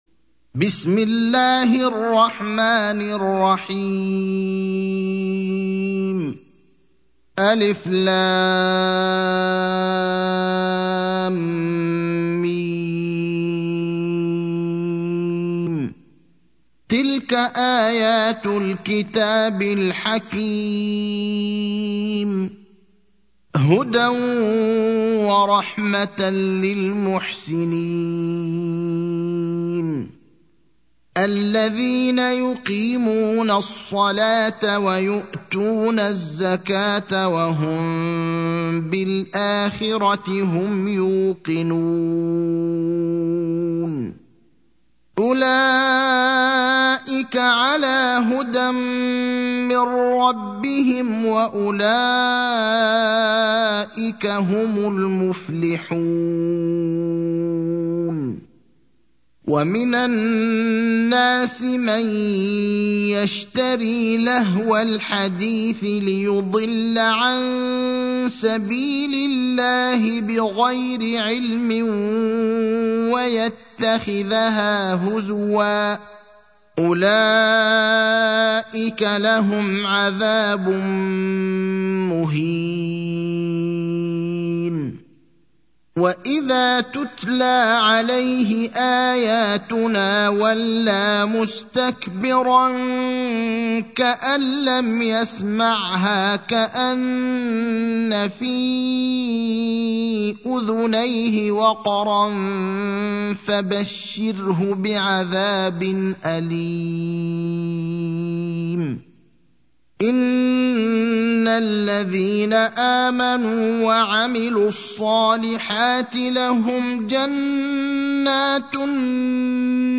المكان: المسجد النبوي الشيخ: فضيلة الشيخ إبراهيم الأخضر فضيلة الشيخ إبراهيم الأخضر لقمان (31)